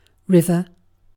river-1-uk.mp3